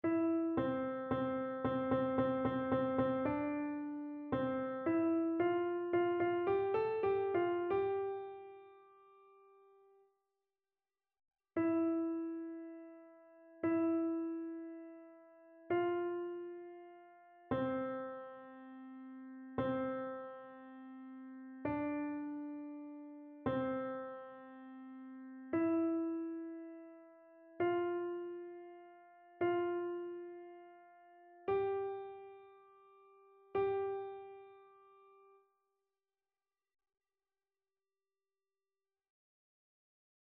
AltoTénorBasse
annee-abc-temps-pascal-ascension-du-seigneur-psaume-46-alto.mp3